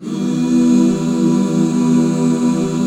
E MIN -R.wav